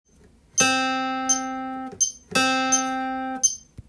２拍伸ばして裏
譜面は全てドの音だけで表記していますので
８分休符をいれて８分音符になります。